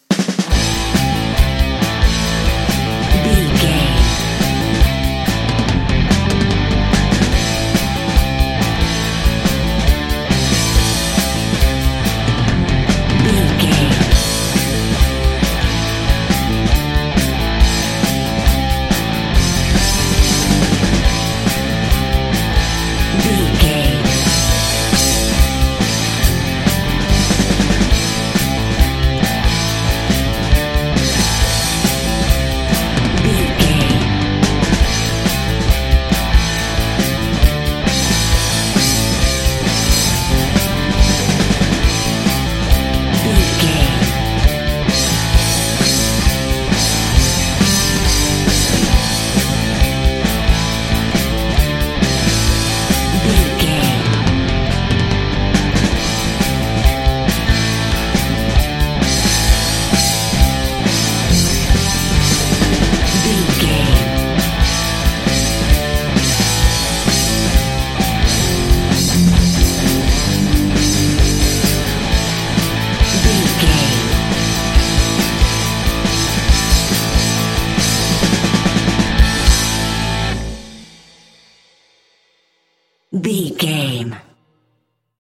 Ionian/Major
electric guitar
drums
bass guitar
hard rock
lead guitar
aggressive
energetic
intense
nu metal
alternative metal